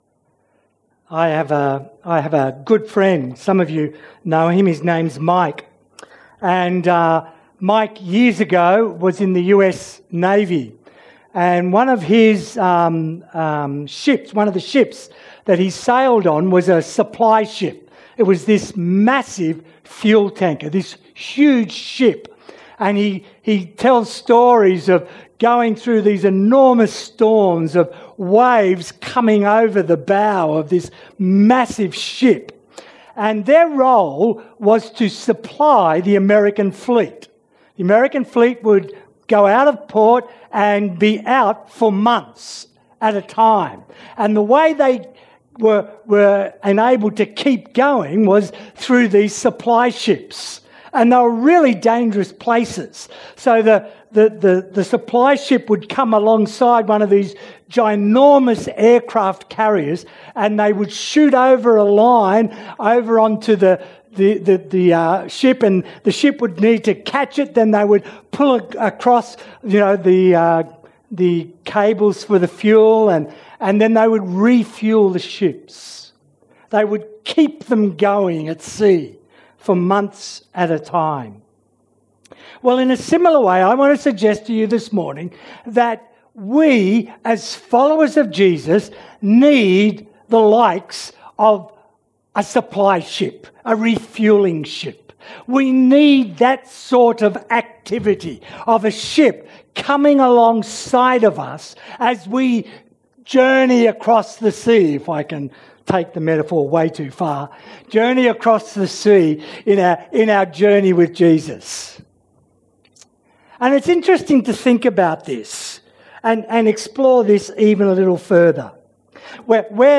Categories Sermon Tags 2018